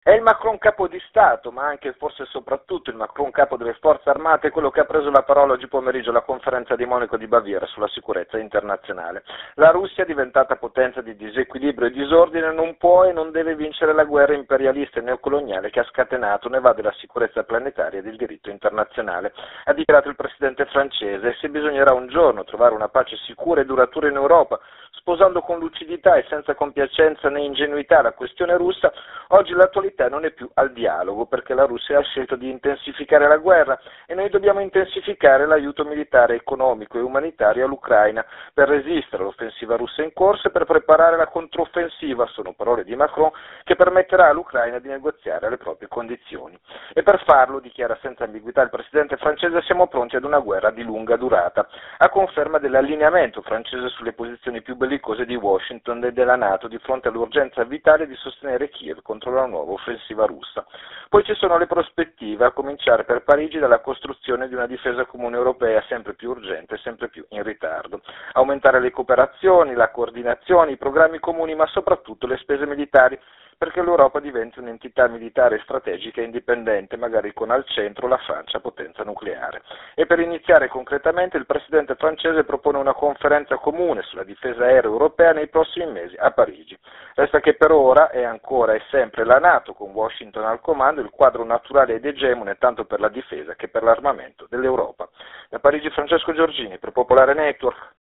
Anche il presidente francese Macron ha preso la parola in apertura, accennando alla necessità di un dialogo, “ma non è ancora il momento” ha specificato. Il servizio da Parigi